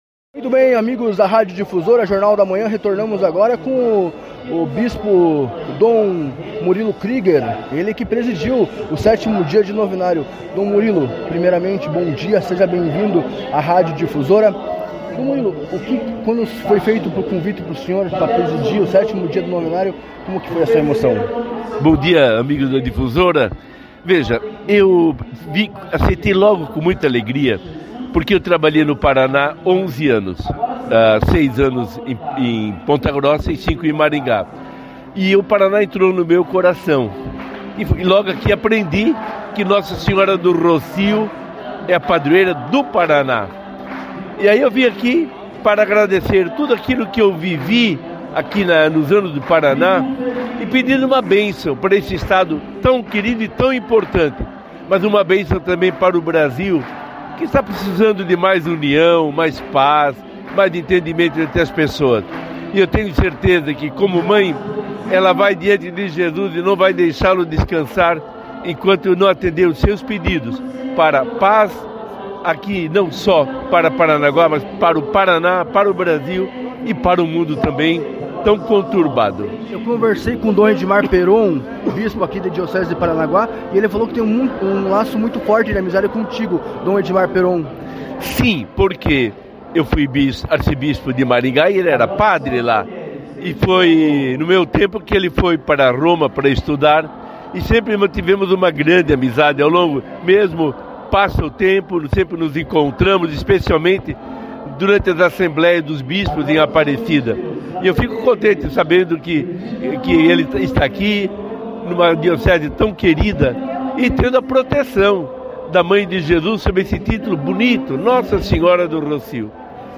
Dom Murilo Krieger, SCJ, arcebispo emérito de São Salvador da Bahia, comentou sua visita em Paranaguá no Santuário de Nossa Senhora do Rocio.